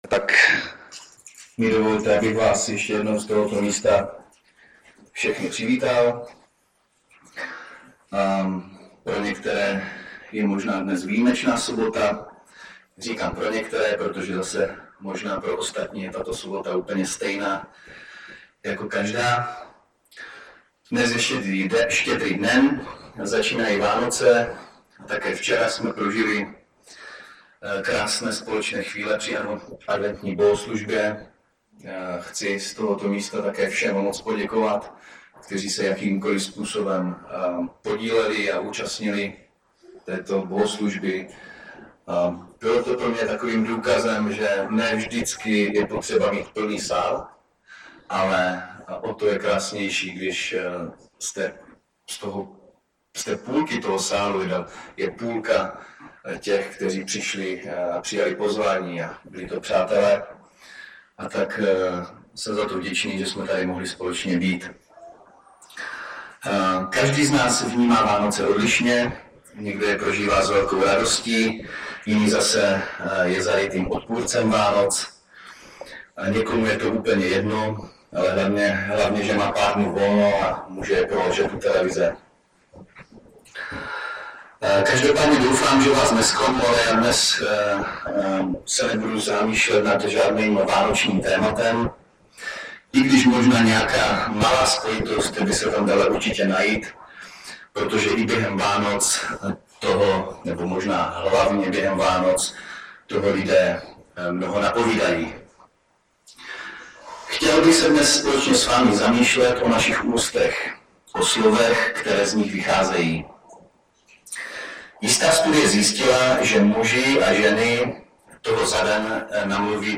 Omluvte sníženou kvalitu zvuku.